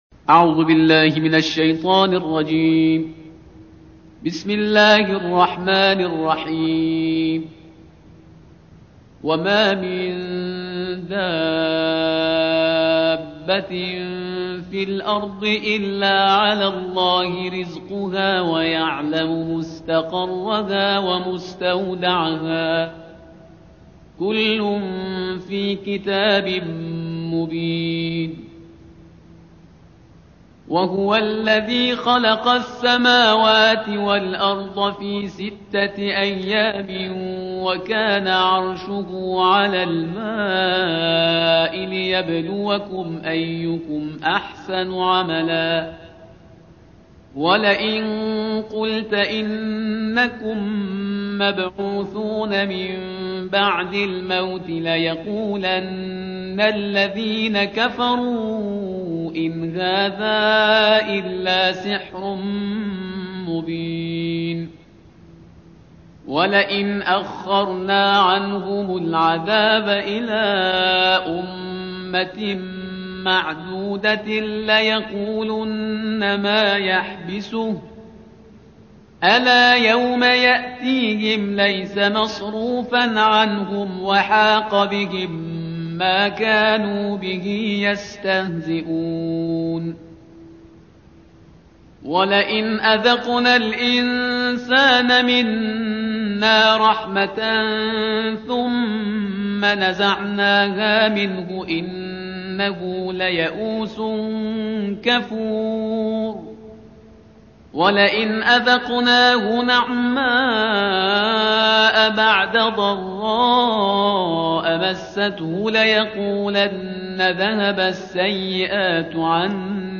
تلاوت ترتیل جزء دوازدهم کلام وحی با صدای استاد